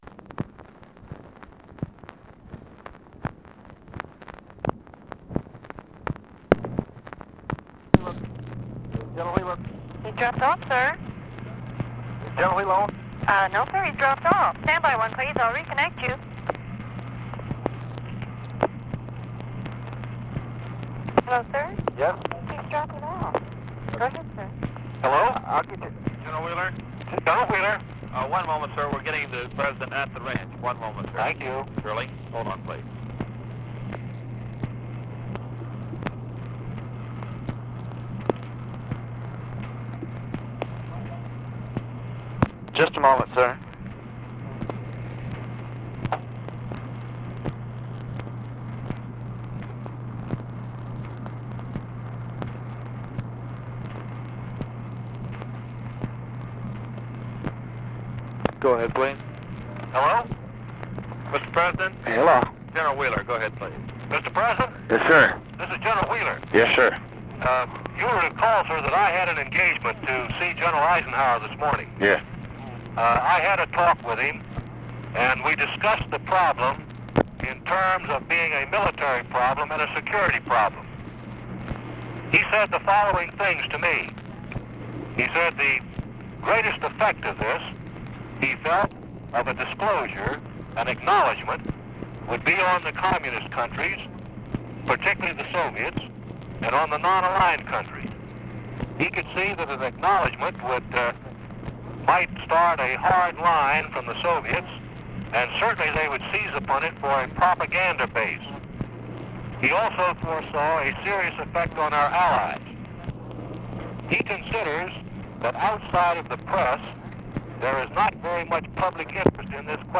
Conversation with EARLE WHEELER, September 26, 1964
Secret White House Tapes